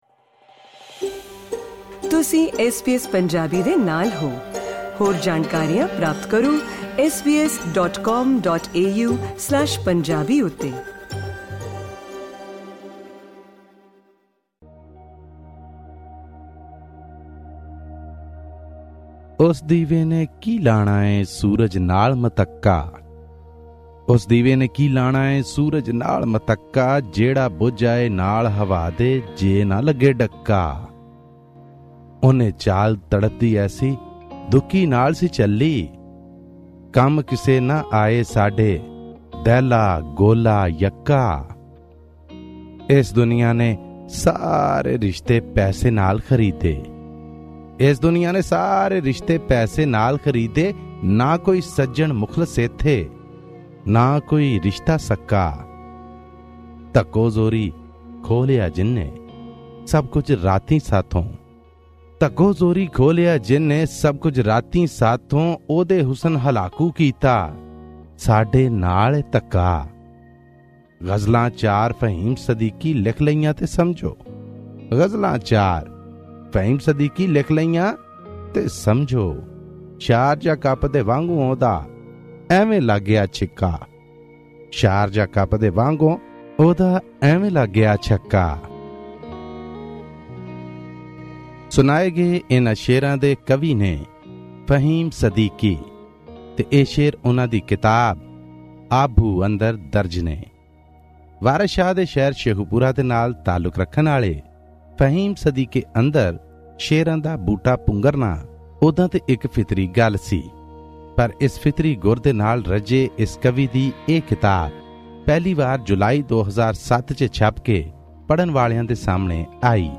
Pakistani Punjabi poetry book review: 'Aabhoo' by Fahim Sadique